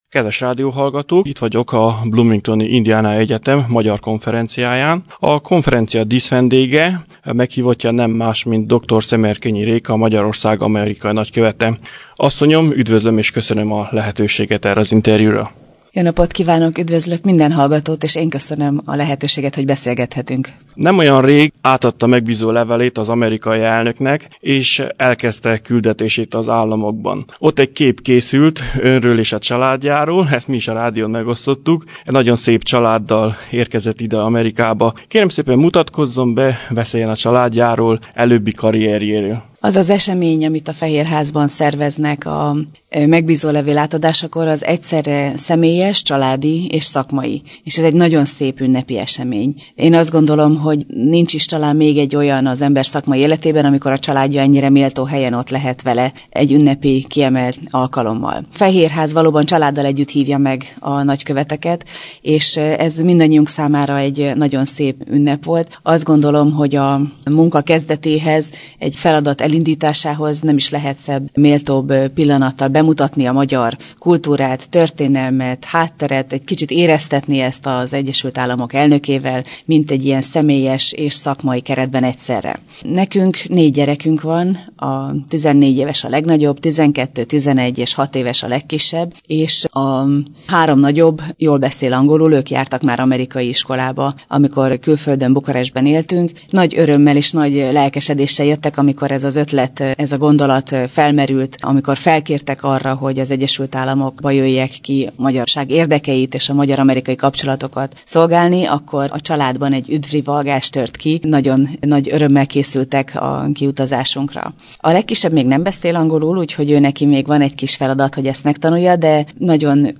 A nagykövet asszony megtisztelt azzal, hogy a nagyon zsúfolt programja közben egy interjút adott a Bocskai Rádiónak: